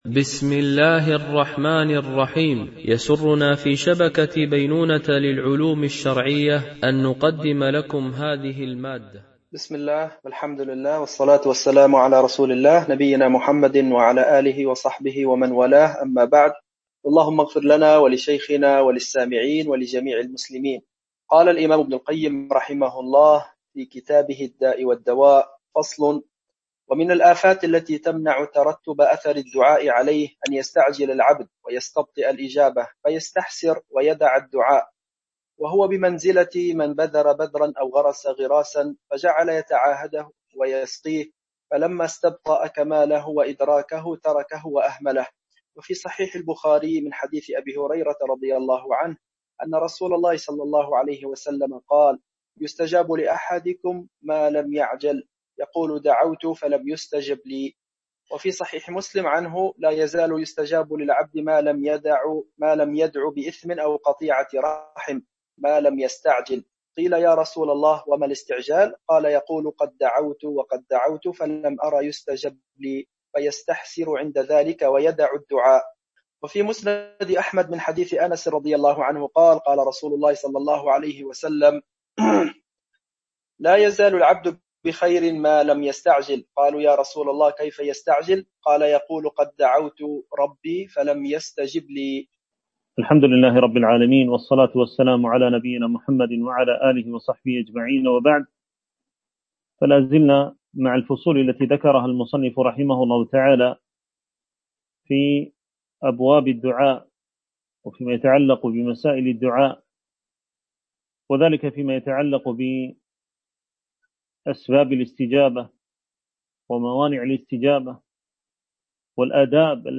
شرح كتاب الداء والدواء ـ الدرس 3